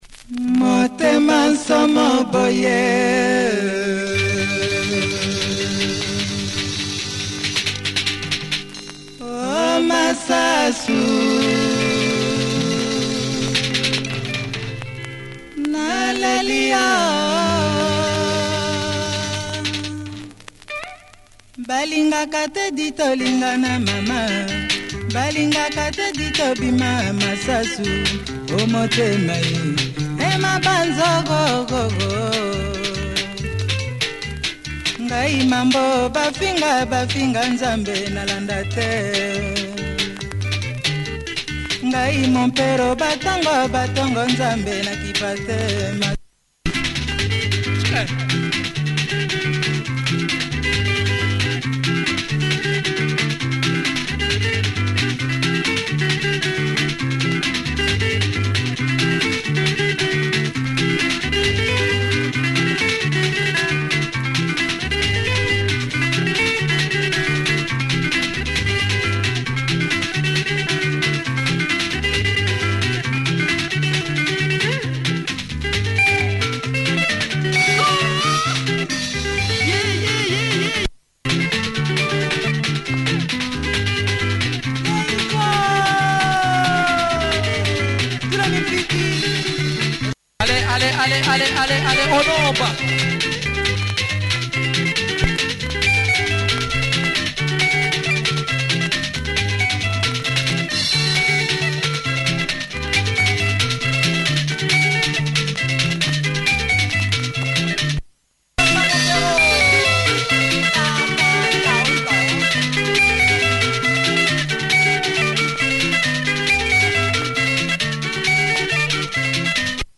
Great shuffle lingala by this famous outfit